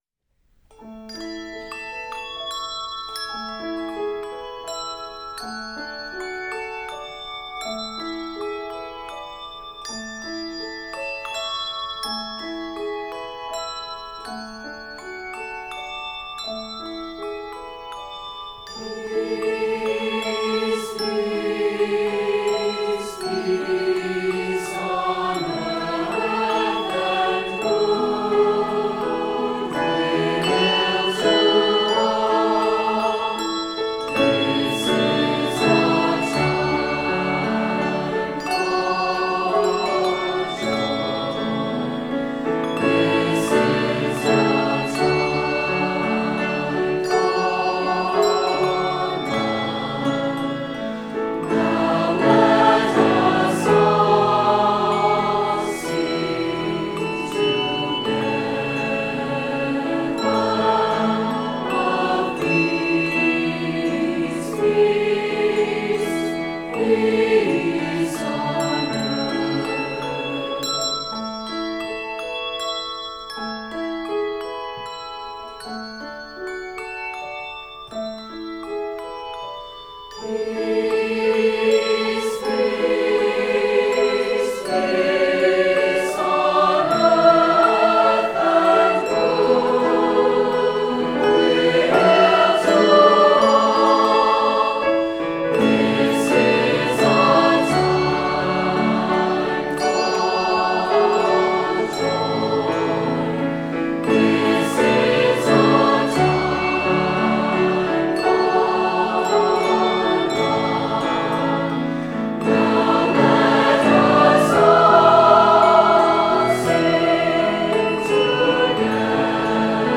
Click here to experience the blending of young voices in the beautiful sound of peace on earth.